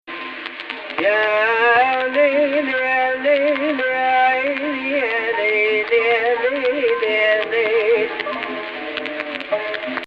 Jins Rast Lower Octave Descending
The melodies on this page descend toward 1, although not all of them do in fact resolve (saving that for a future sentence).
Type: X => 543